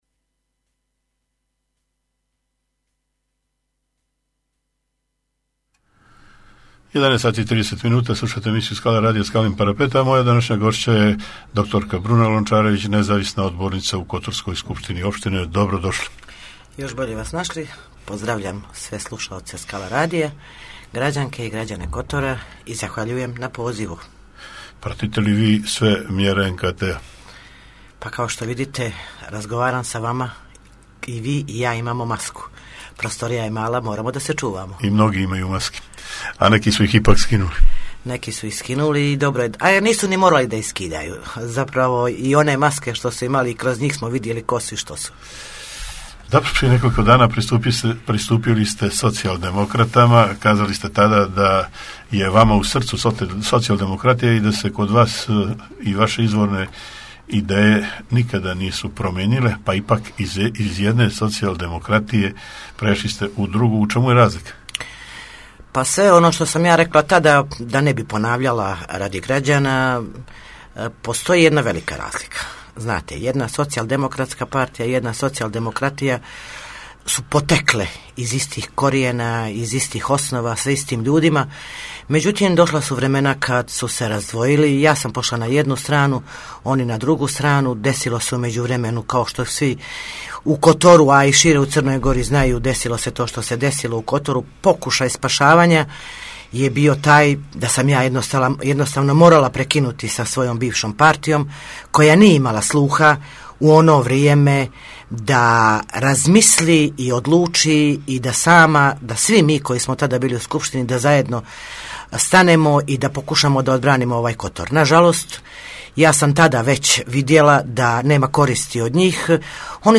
Gošća emisije dr Bruna Lončarević, nezavisna odbornica u SO Kotor